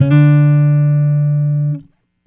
Guitar Slid Octave 03-D2.wav